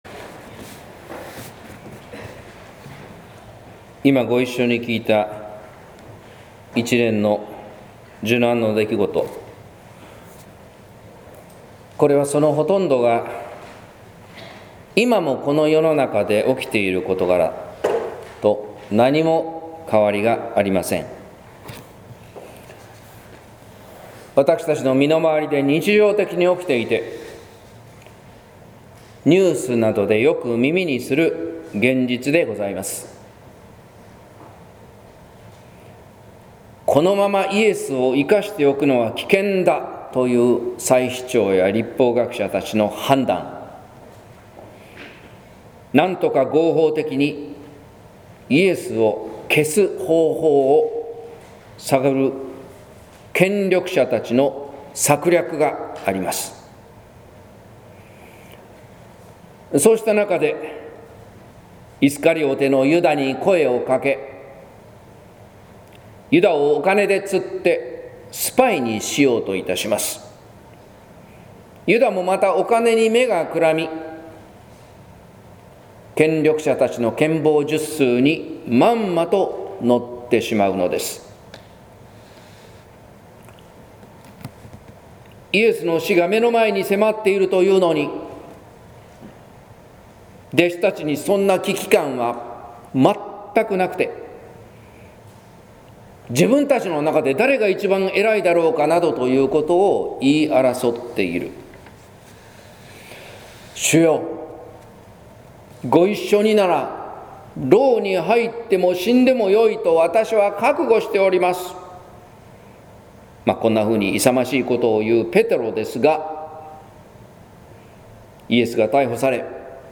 説教「思いの交錯」（音声版）